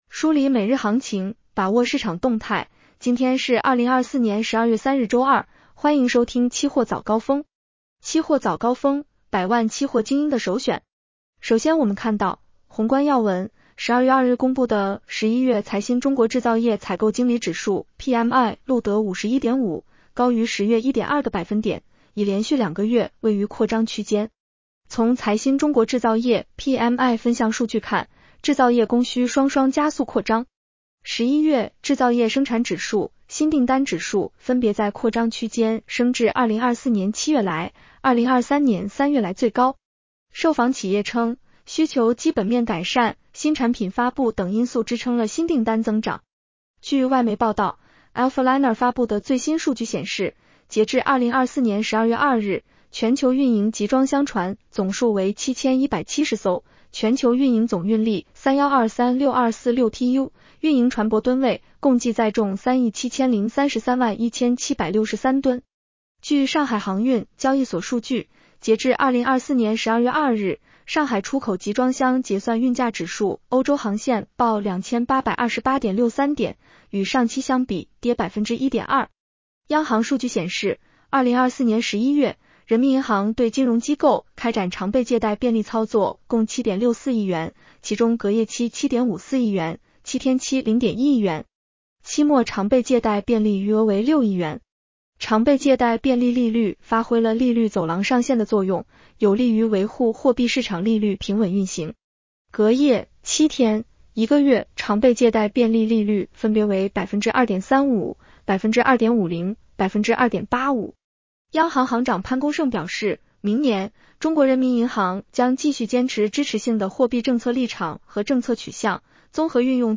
期货早高峰-音频版 女声普通话版 下载mp3 宏观要闻 1. 12月2日公布的11月财新中国制造业采购经理指数（PMI） 录得51.5，高于10月1.2个百分点，已连续两个月位于扩张区间。